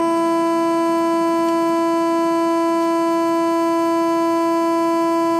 Nintendo Wii Sound Of Death